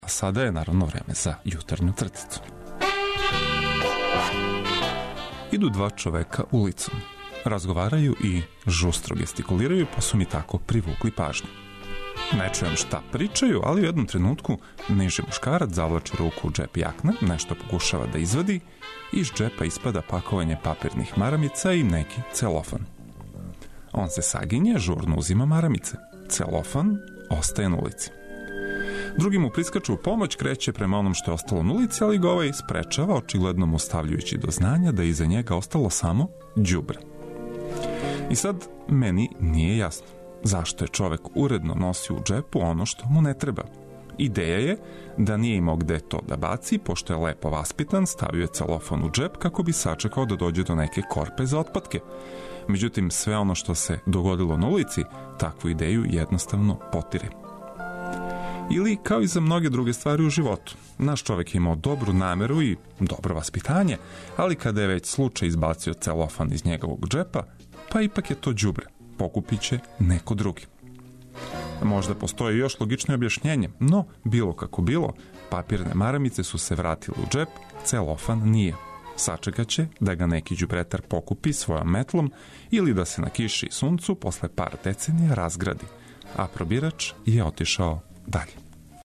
И за једне и за друге овог јутра ћемо вам понудити добру музику и неке интересантне приче, као и најнужније информације!